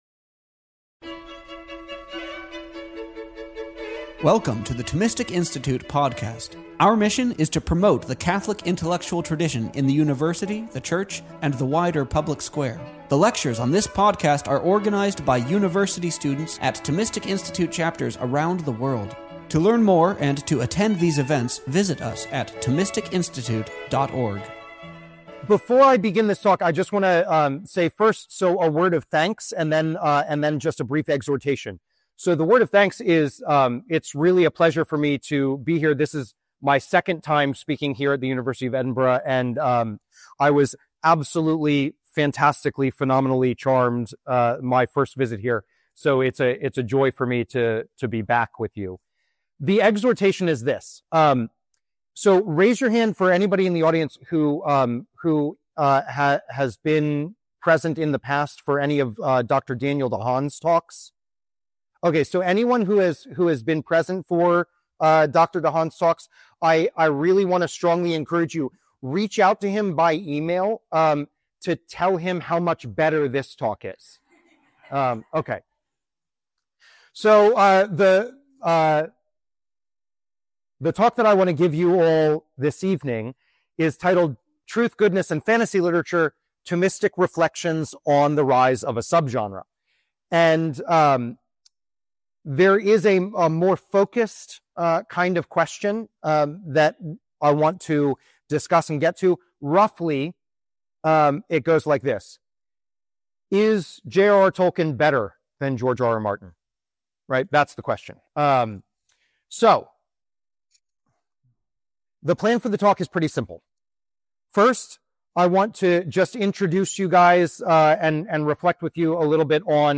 This lecture was given on March 30th, 2023, at Harvard University.